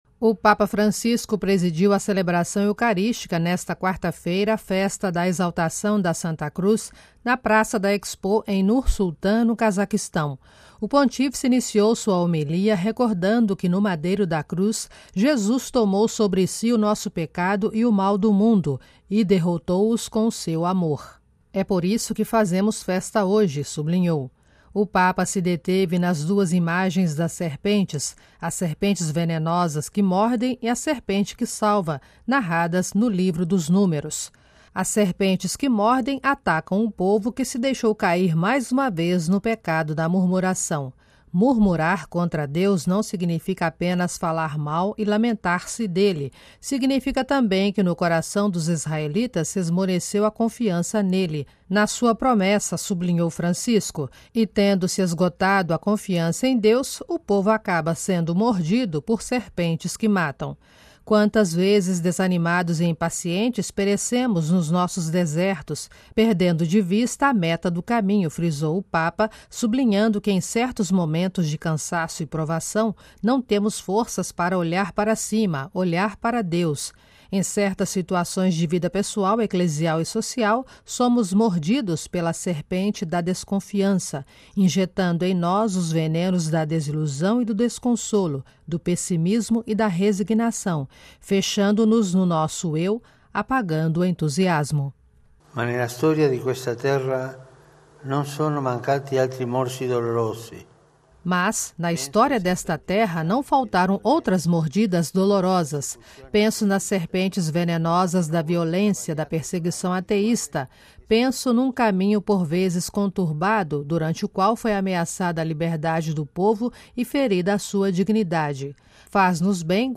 O Papa Francisco presidiu a celebração eucarística, nesta quarta-feira (14/09), Festa da Exaltação da Santa Cruz, na Praça da Expo, em Nur-Sultan, no Cazaquistão.